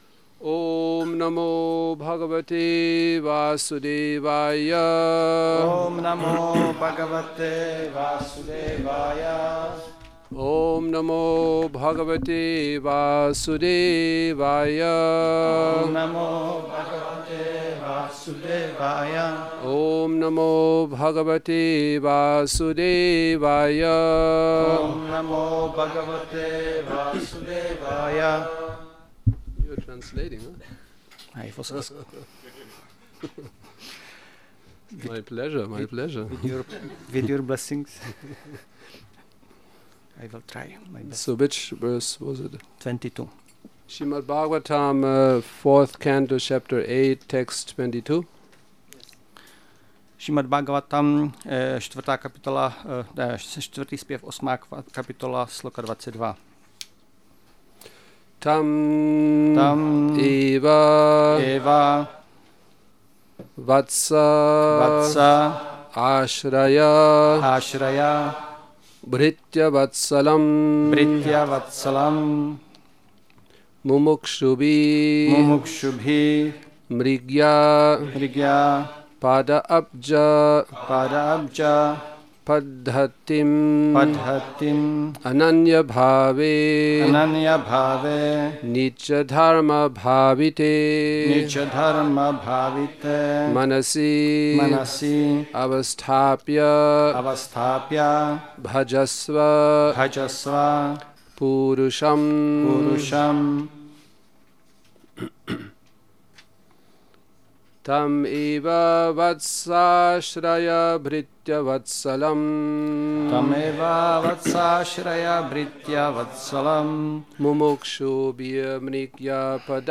Přednáška SB-4.8.22 – Šrí Šrí Nitái Navadvípačandra mandir